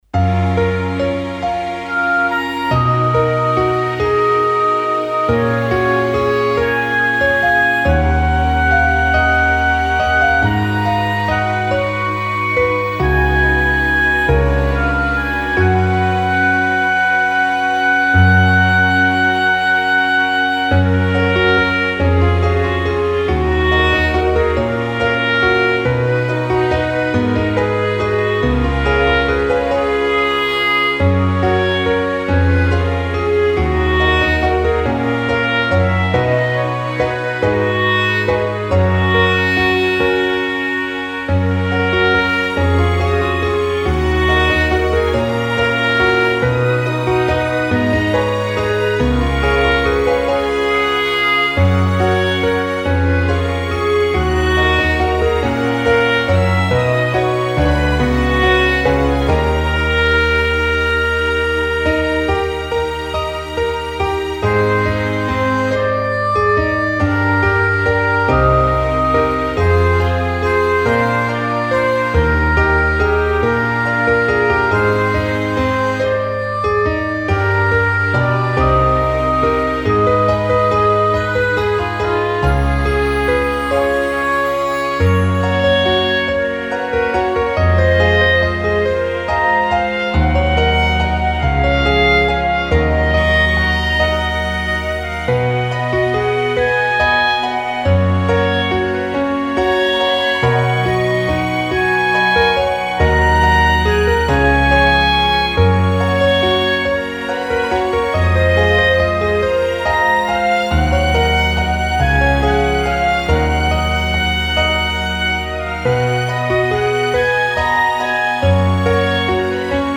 フリーBGM 街・拠点・村など のんびり・ほのぼの
フェードアウト版のmp3を、こちらのページにて無料で配布しています。